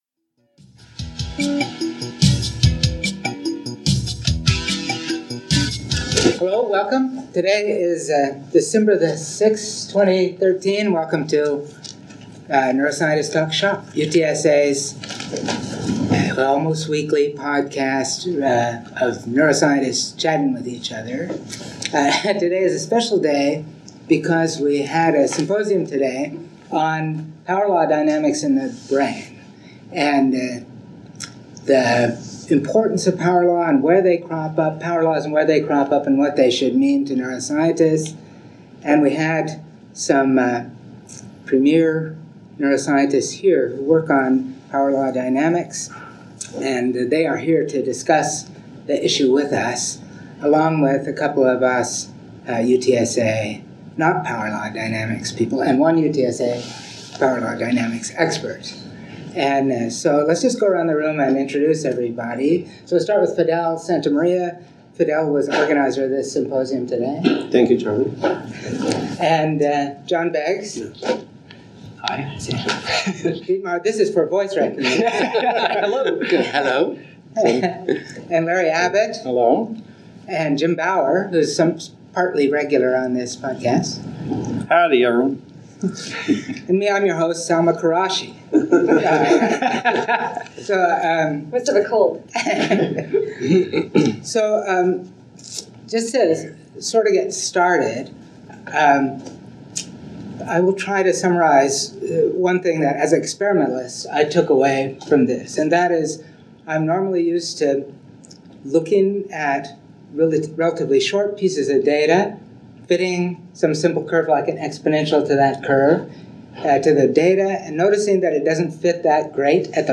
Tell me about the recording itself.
Symposium 2014: Power Law Dynamics in the Brain